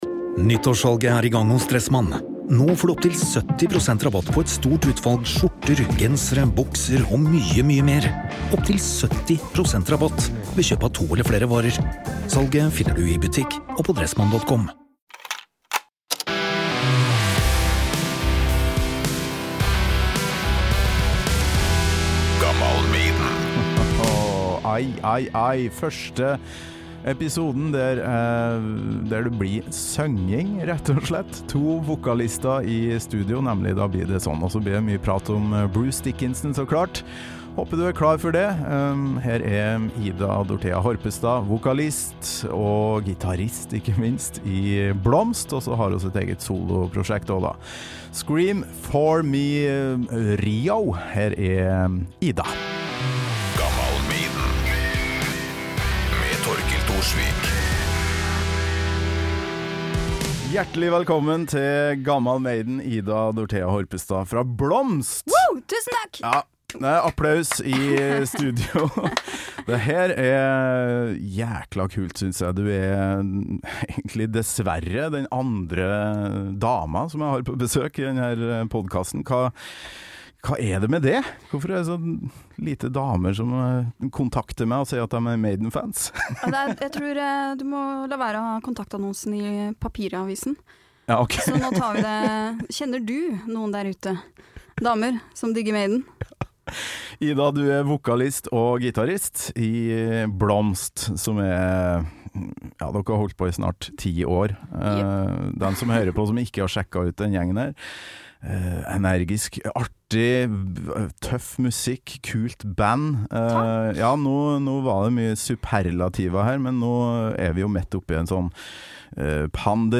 Sånt som skjer når to vokalister som elsker Bruce Dickinson møtes for å prate om frontfigur-magi, historieformidling, Maiden i traktor og The Trooooper.